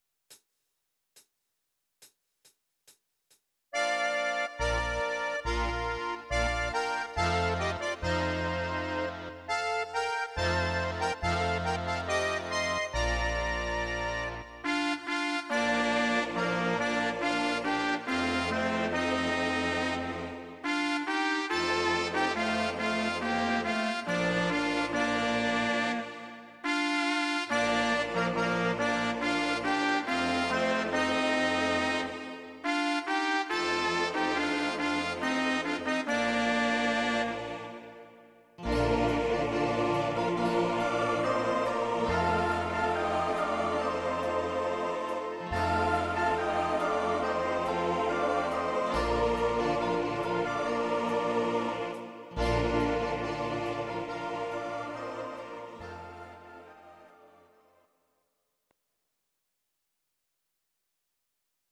Audio Recordings based on Midi-files
German, Traditional/Folk, Volkst�mlich